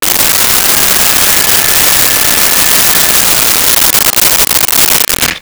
Blender On Mix
Blender on Mix.wav